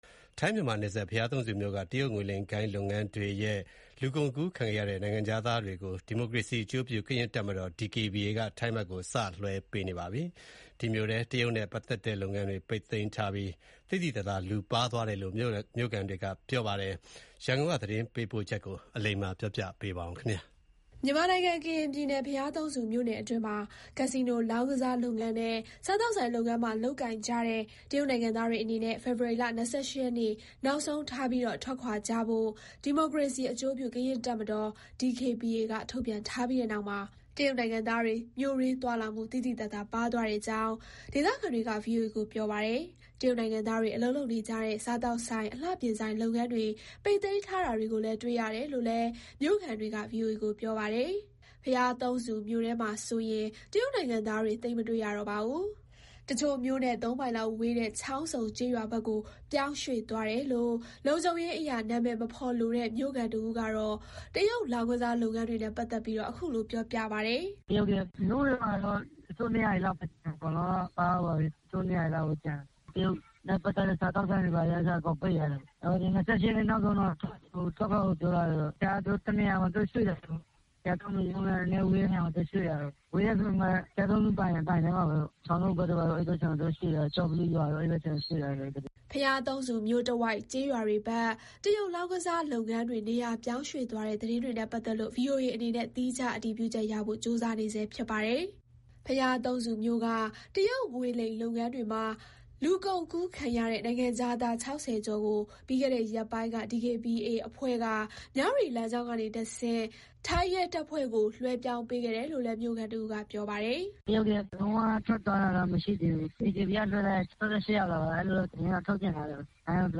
ဘုရားသုံးဆူမြို့က တရုတ် အွန်လိုင်းငွေလိမ်လုပ်ငန်းတွေအခြေအနေ သတင်းပေးပို့ချက်ကို တင်ပြထားပါတယ်။